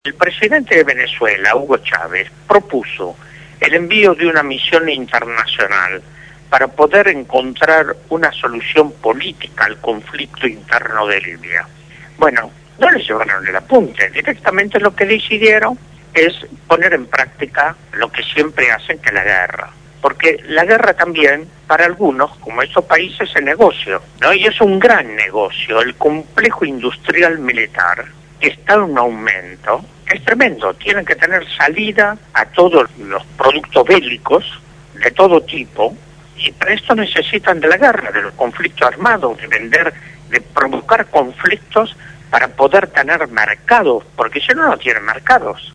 Adolfo Pérez Esquivel fue entrevistado en el Programa «Punto de Partida» (Lunes a viernes 7 a 9 de la mañana) de Radio Gráfica. En la entrevista habló sobre la invasión a Libia, el rol de la ONU, el complejo militar industrial y la hipocresía de las potencias centrales que eran aliados de Kadafi hasta hace algunas semanas.